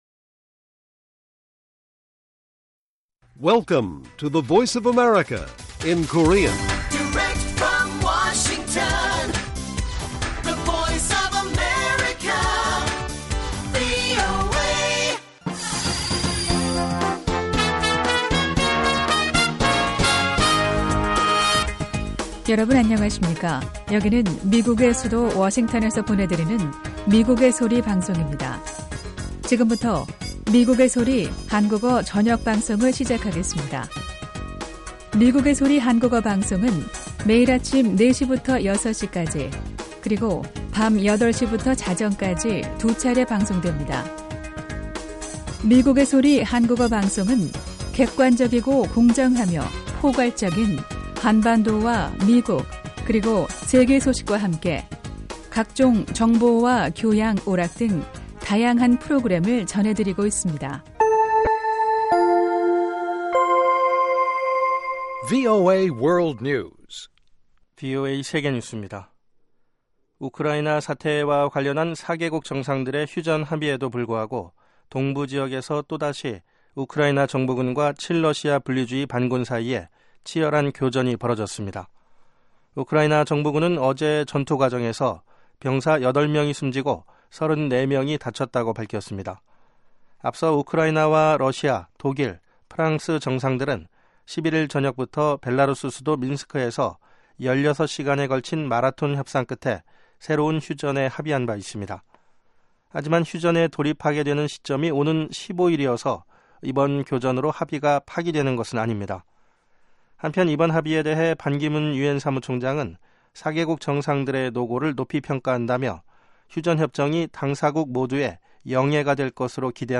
VOA 한국어 방송의 간판 뉴스 프로그램 '뉴스 투데이' 1부입니다. 한반도 시간 매일 오후 8시부터 9시까지 방송됩니다.